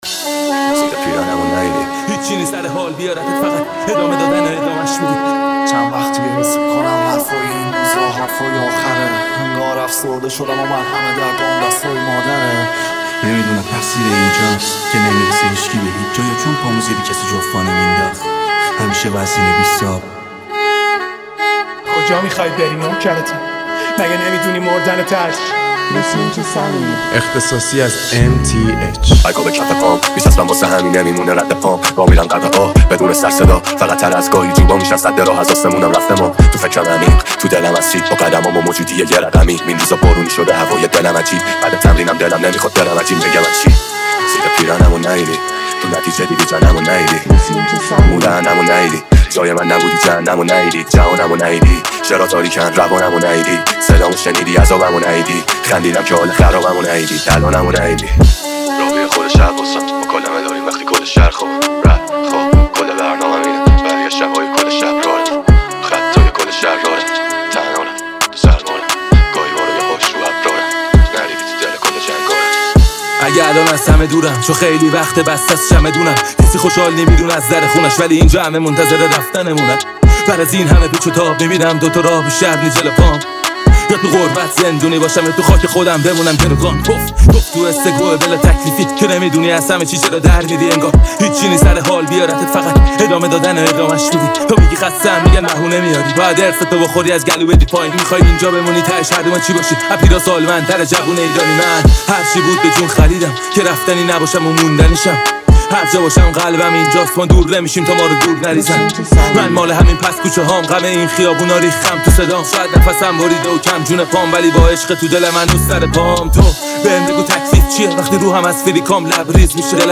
ریمیکس جدید رپ